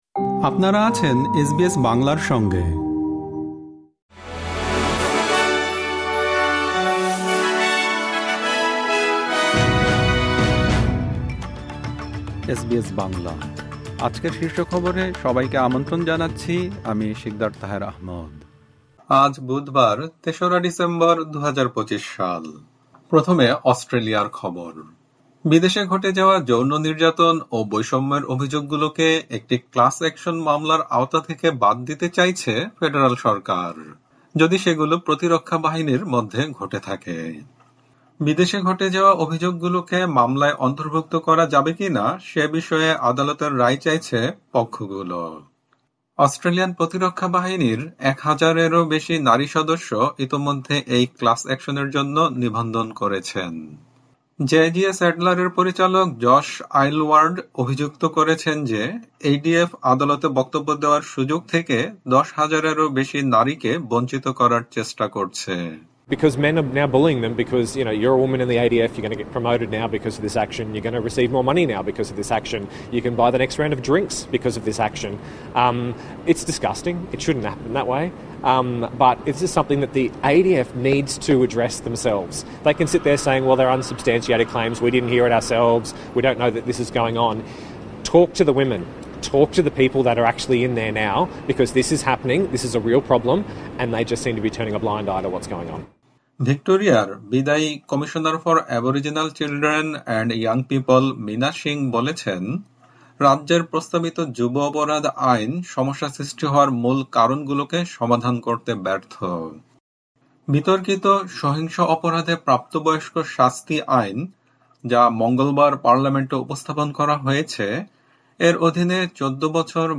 আজকের শীর্ষ খবর বিদেশে ঘটে যাওয়া যৌন নির্যাতন ও বৈষম্যের অভিযোগগুলোকে একটি ক্লাস অ্যাকশন মামলার আওতা থেকে বাদ দিতে চাইছে ফেডারাল সরকার। অস্ট্রেলিয়ার অর্থনৈতিক প্রবৃদ্ধি আগামী দিনে আরও গতিশীল হবে বলে ধারণা করা হচ্ছে। পিঠের চোটের কারণে অ্যাশেজের দ্বিতীয় টেস্ট থেকে বাদ পড়েছেন উসমান খাজা।